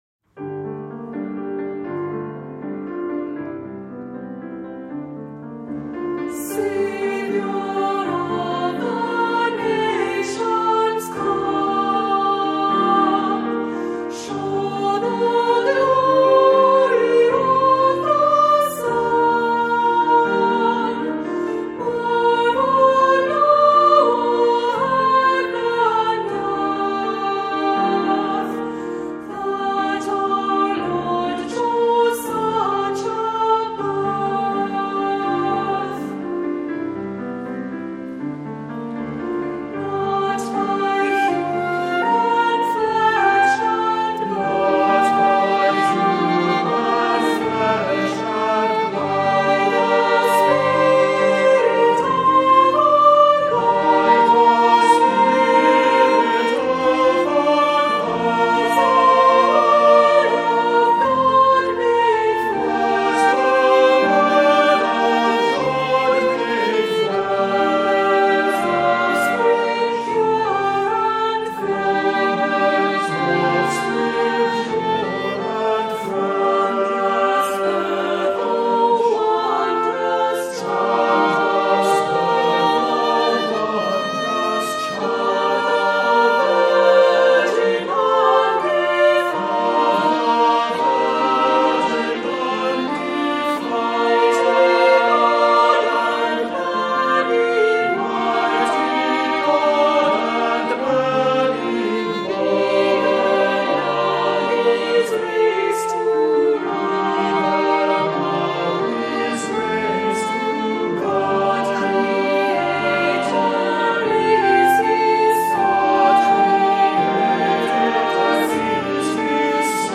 Voicing: SATB: Tenor Descant